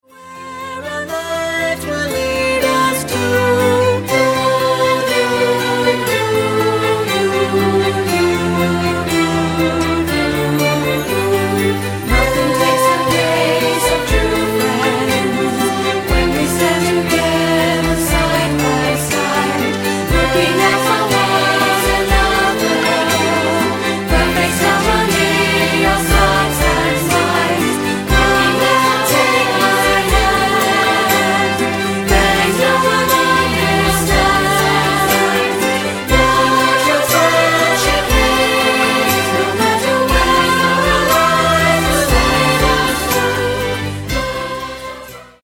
reproducible choral music for your elementary choir